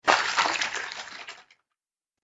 دانلود صدای قایق 6 از ساعد نیوز با لینک مستقیم و کیفیت بالا
جلوه های صوتی